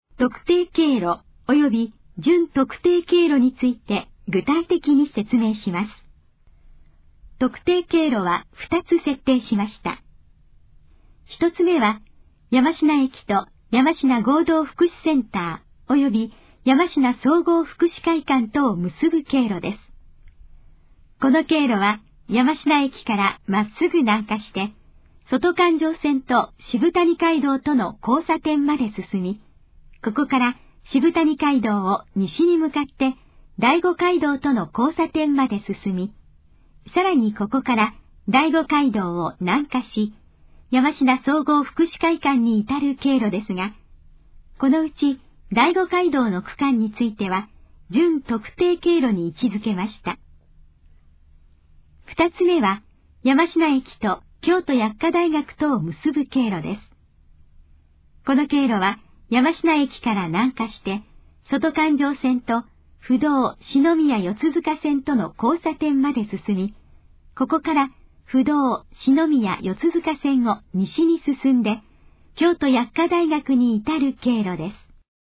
以下の項目の要約を音声で読み上げます。
ナレーション再生 約151KB